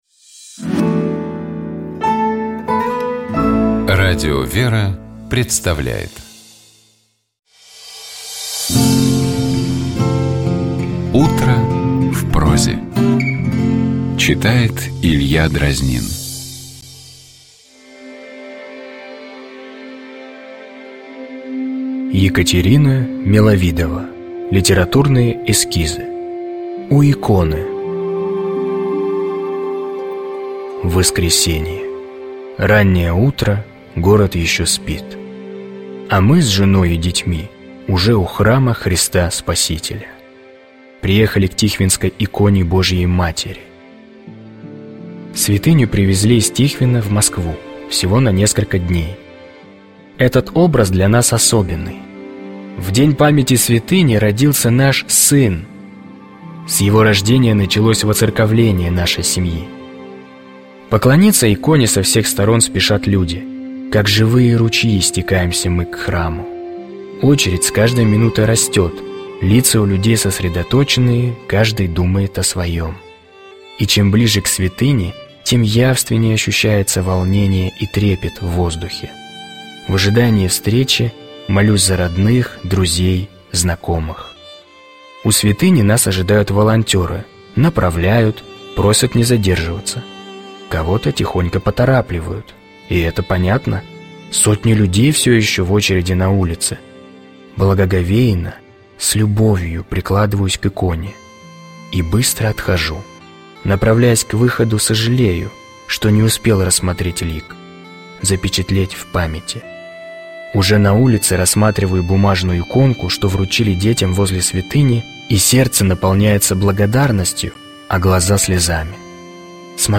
О Христе как Спасителе и пути в Царствие Небесное, — епископ Тольяттинский и Жигулёвский Нестор.